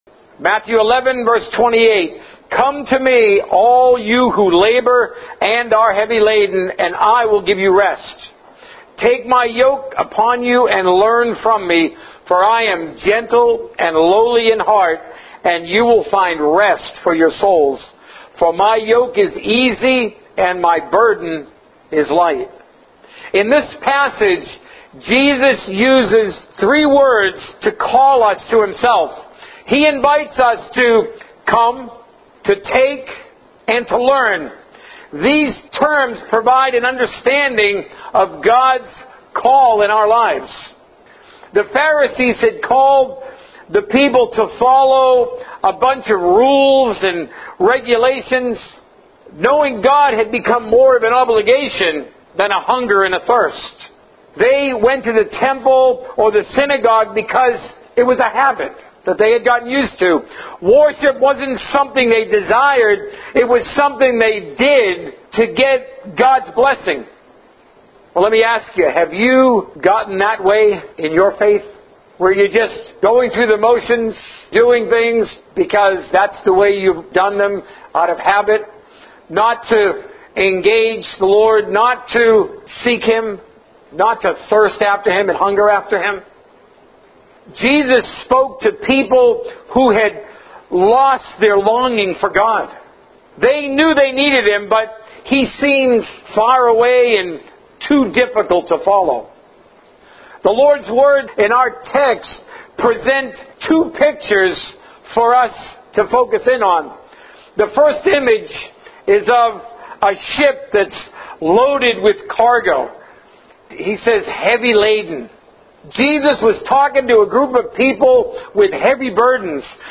A message from the series "It's Time to Get a Life."